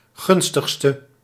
Ääntäminen
France: IPA: [mɛ.jœʁ]